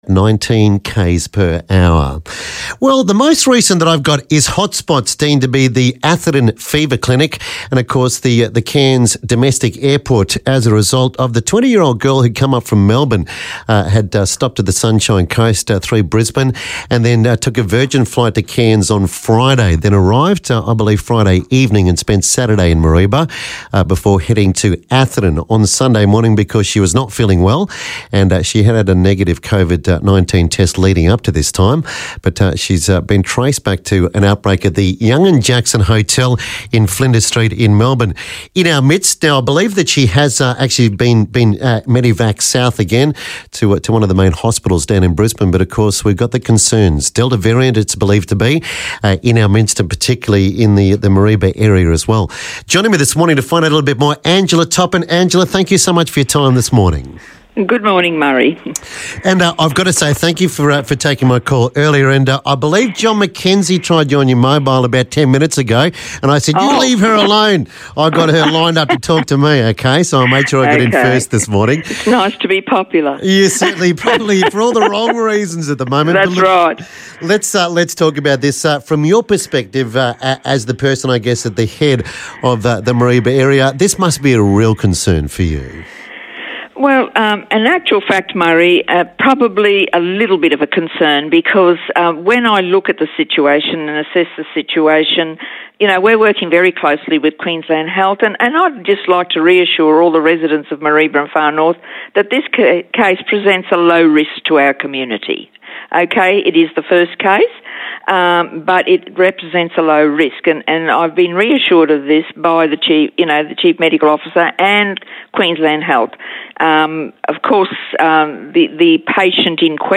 speaks with Mayor of Mareeba Shire Council Angela Toppin about the Covid-19 case in Mareeba